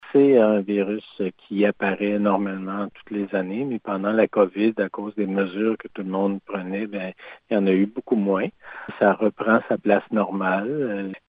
Yv Bonnier-Viger explique pourquoi le VRS est si présent cette année :